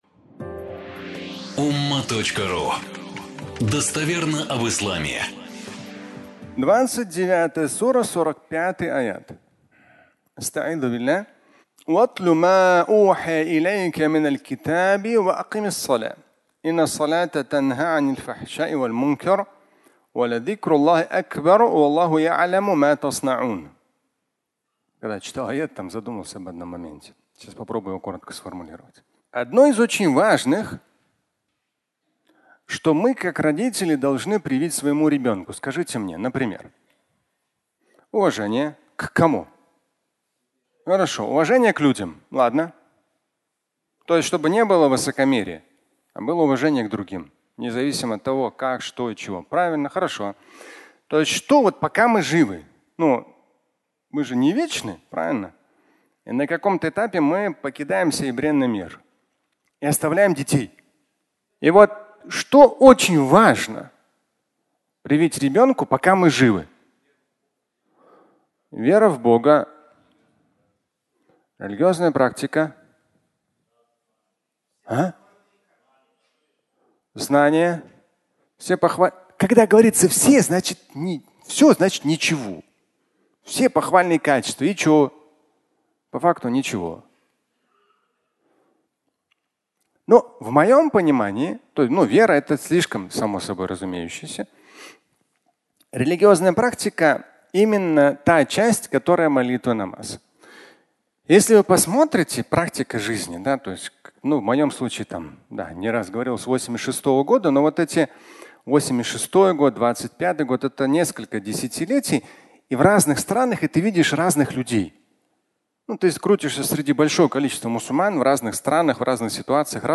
(аудиолекция)